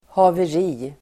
Ladda ner uttalet
Uttal: [haver'i:]